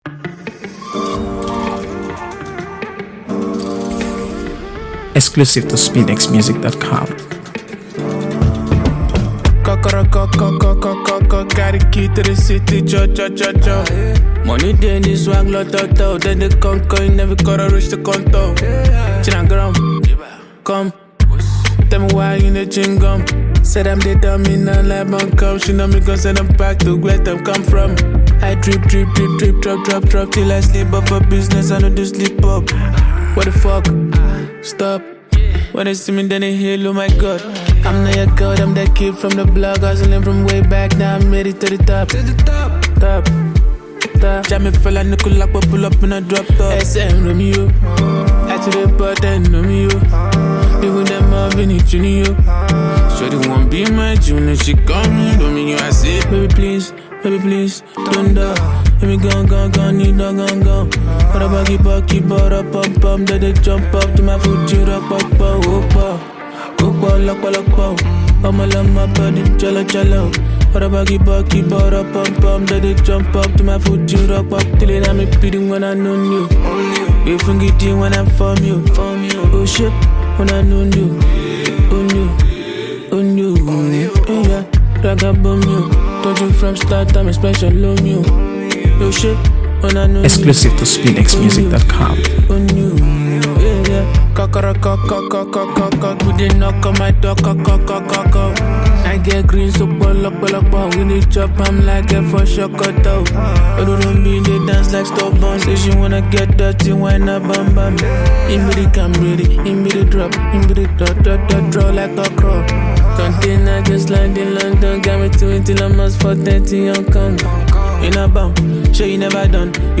AfroBeats | AfroBeats songs
a smooth, emotionally rich track